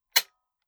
Foley / 30-30 Lever Action Rifle - Dry Trigger 002.wav